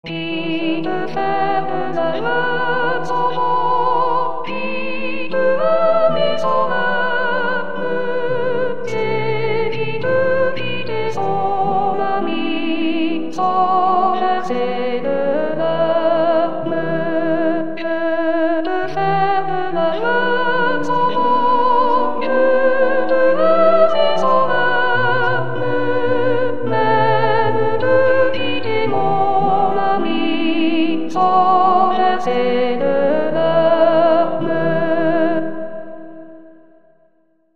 Sop Sans Verser de Larme - A..mp3